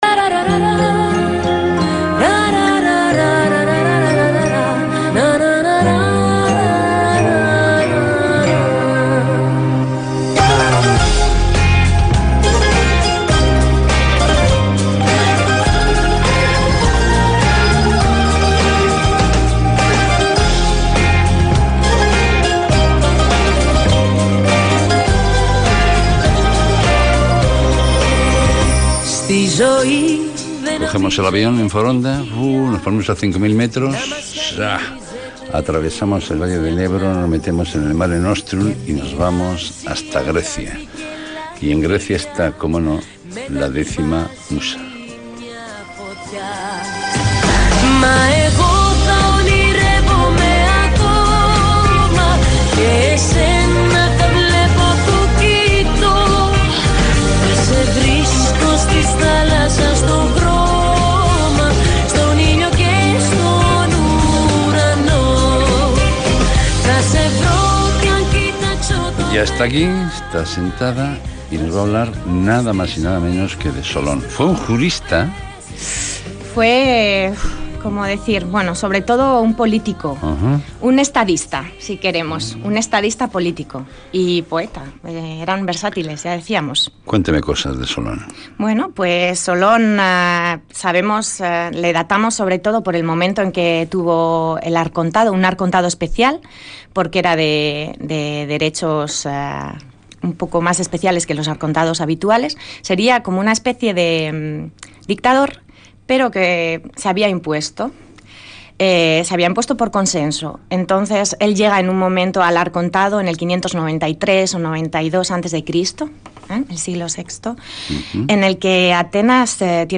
Leemos una elegía de Solón de Atenas haciendo apología de su forma de gobierno.